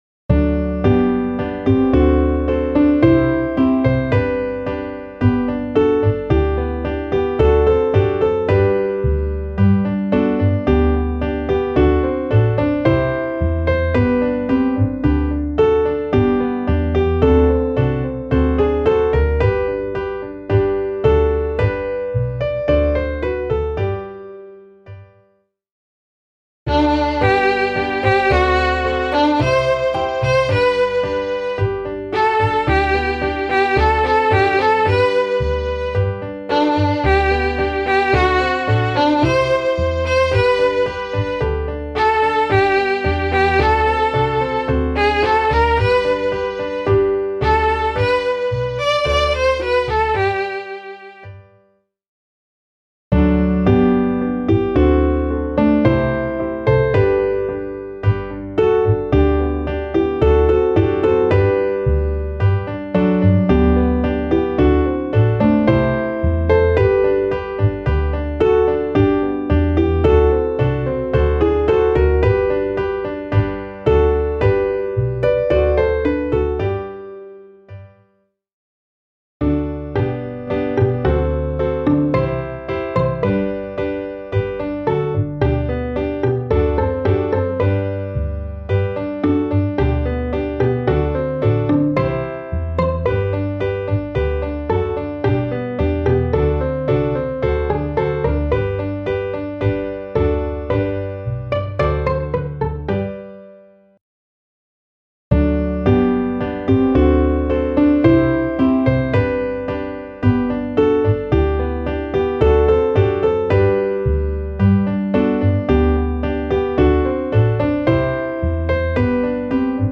Klaviersatz